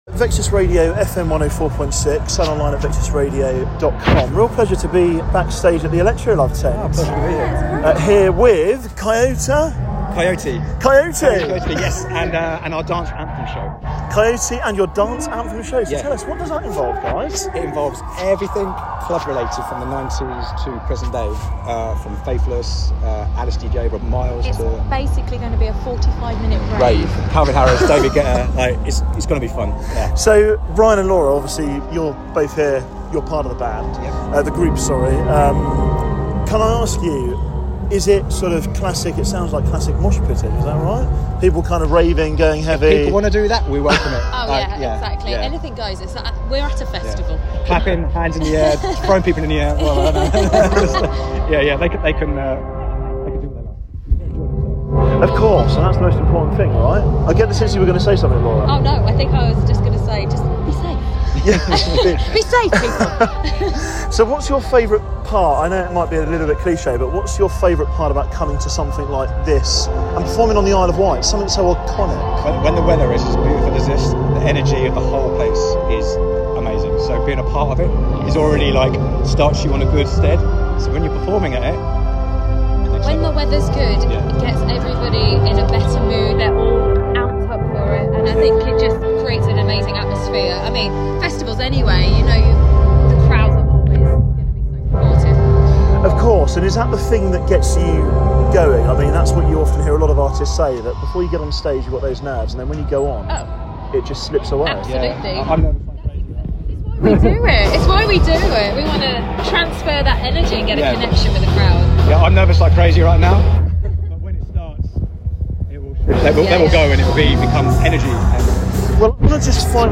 Backstage at Electro Love